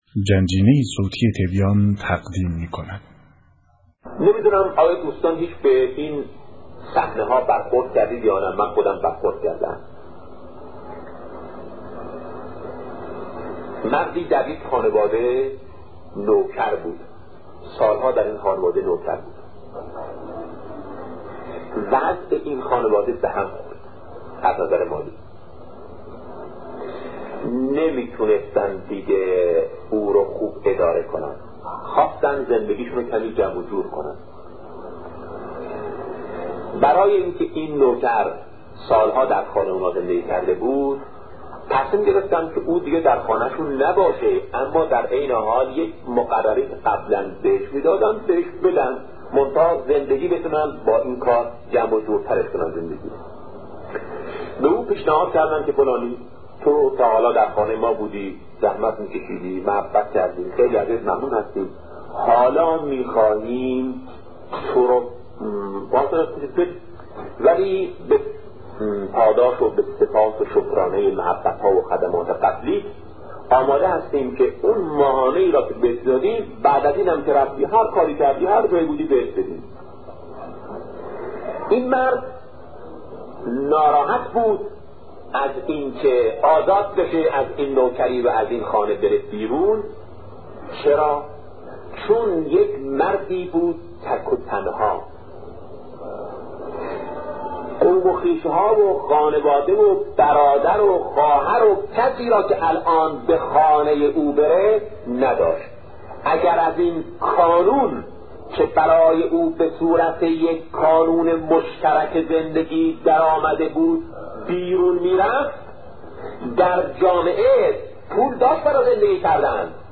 سخنرانی شهید بهشتی(ره) - با موضوع حقیقت خاتمیت- بخش‌دوم